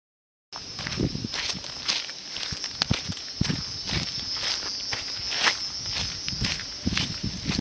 遛狗各种脚步声
描述：和小狗一起散步，走在不同的地面上......然后河流，水......开始有鸟的叫声和一辆听起来很远的卡车......记录器 ：Tascam DR40（内部话筒 XY位置 带防风毛衣）
标签： 脚步 自然 实地录音 步行 遛弯
声道立体声